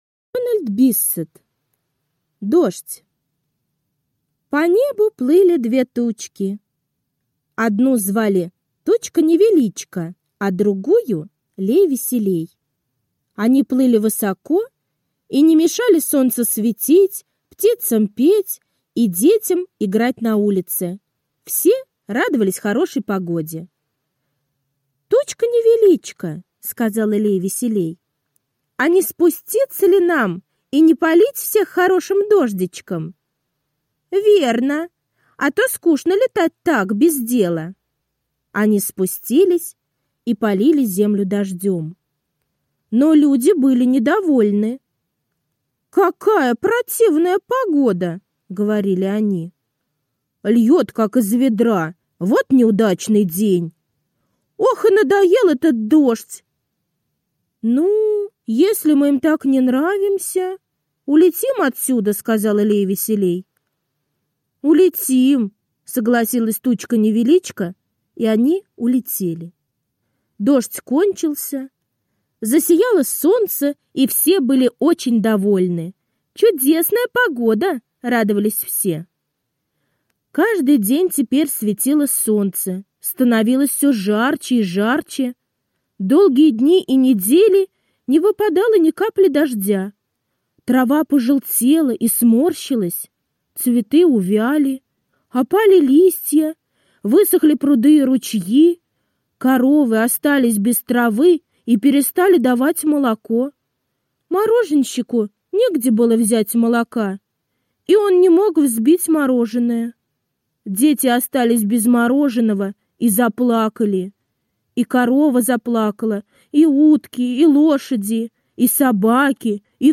Дождь - аудиосказка Биссета Д. Сказка про то, как тучки обиделись на людей и улетели.